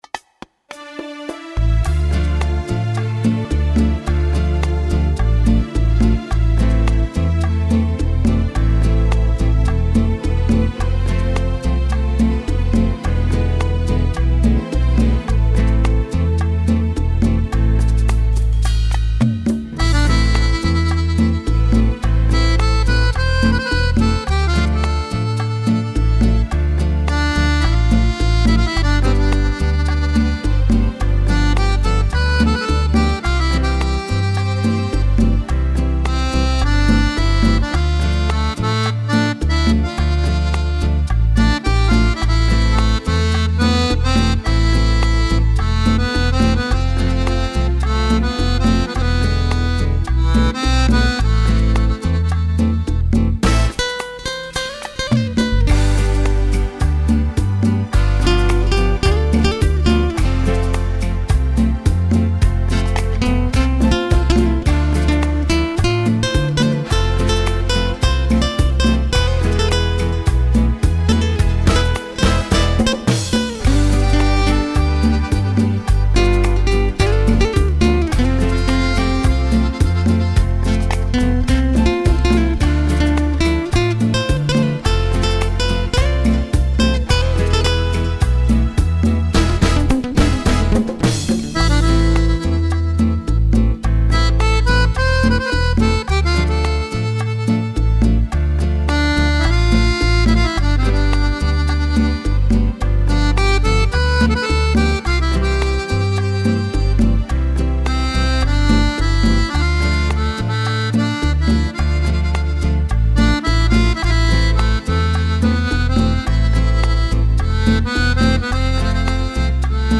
Rumba Bolero